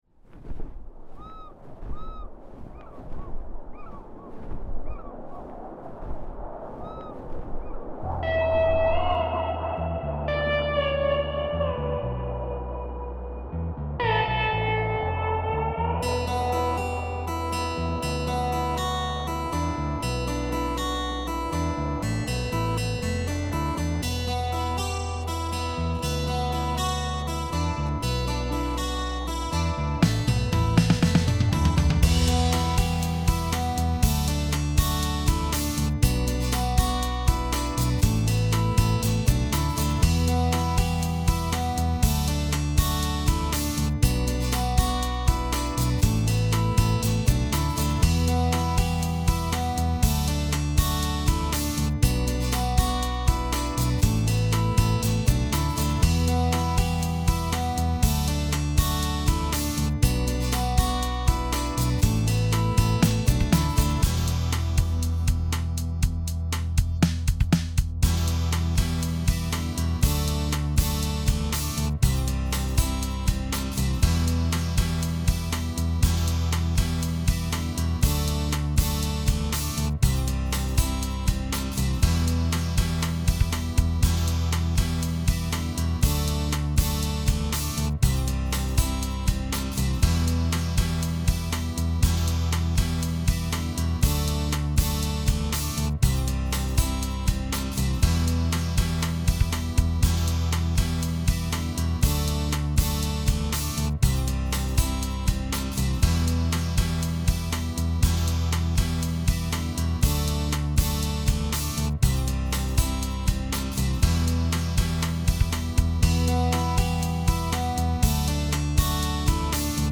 Intro Slide guitar